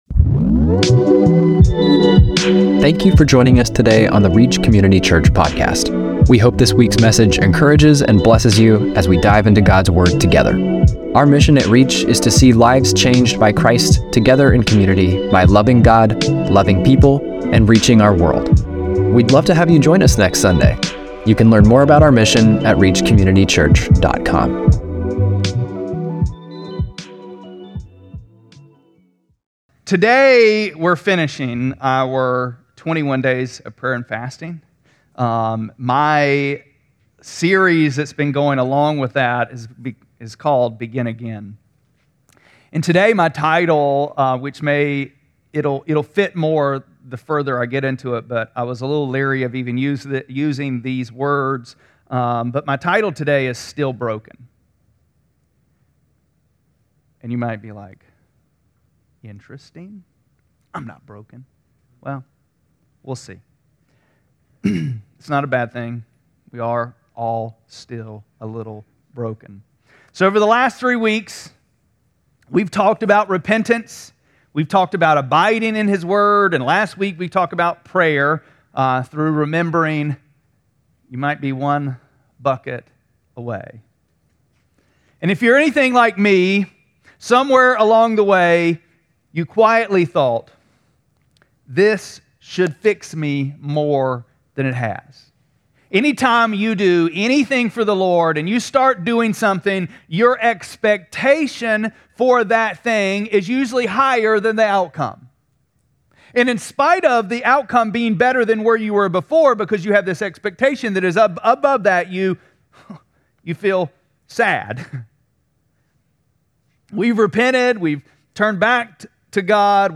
1-27-26-Sermon.mp3